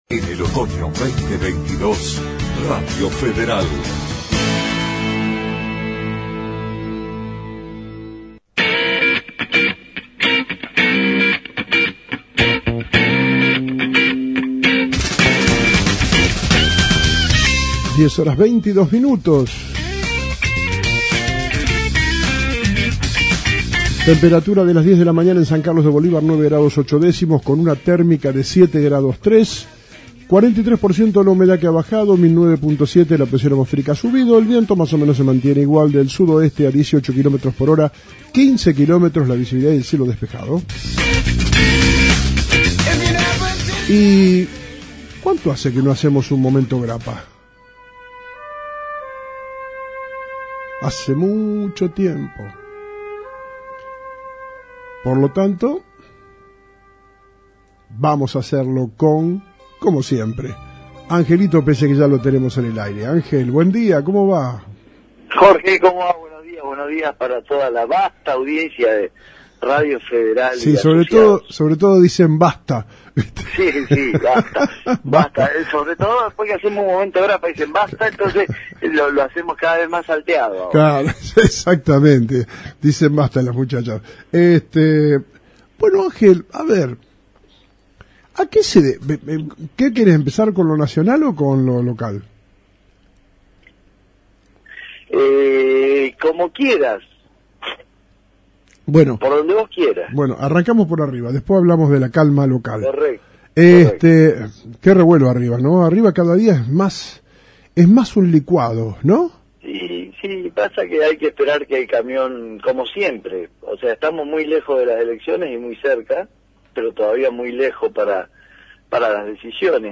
Momento Grappa - Análisis Político :: Radio Federal Bolívar